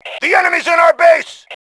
Male5